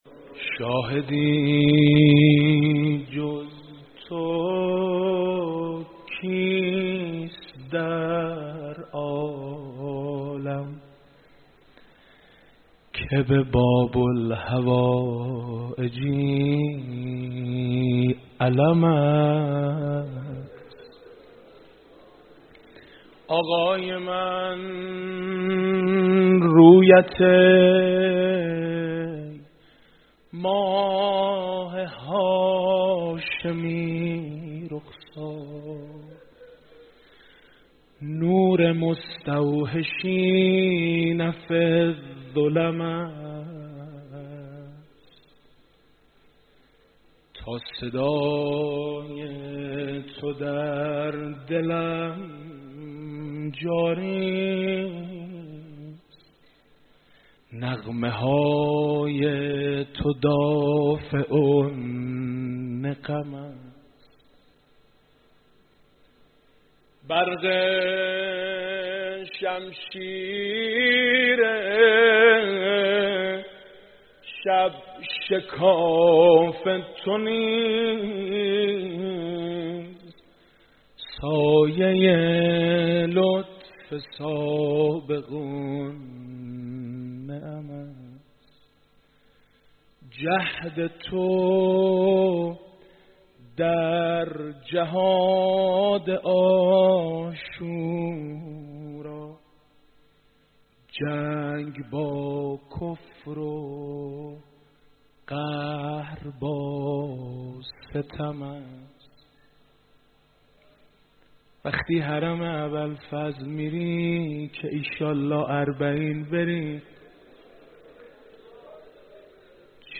صوت /مداحی مطیعی درمحضر رهبرانقلاب
مداحی حاج میثم مطیعی در عزاداری شب تاسوعا در حسینیه امام خمینی (ره)